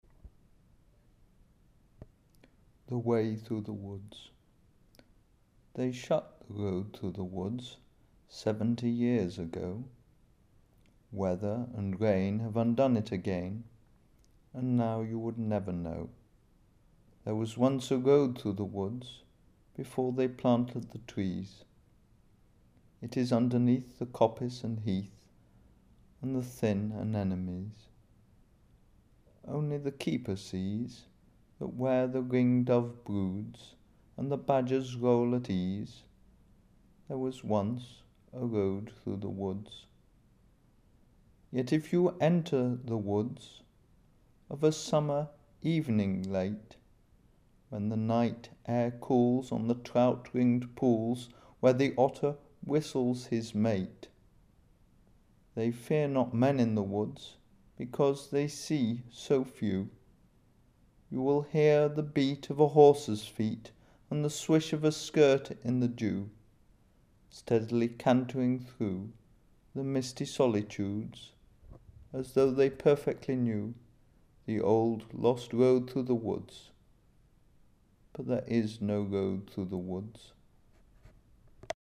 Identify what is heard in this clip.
Tags: poetry speeches